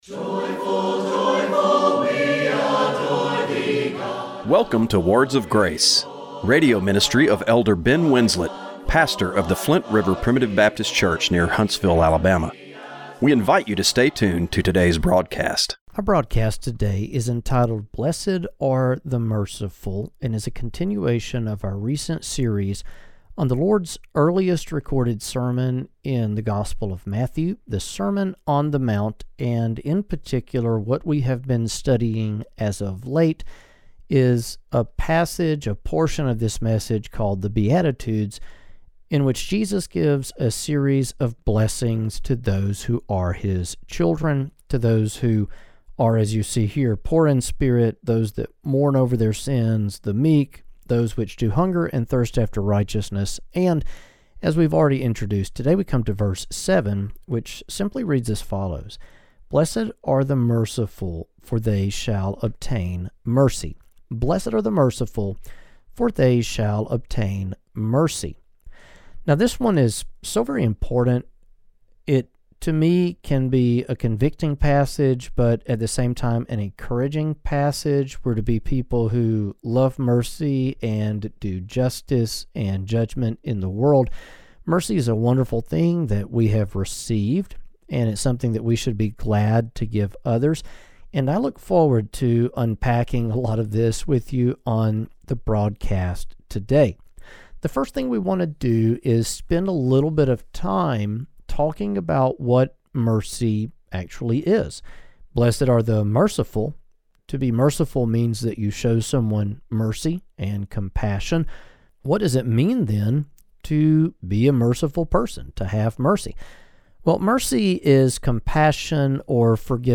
Radio broadcast for March 9, 2025.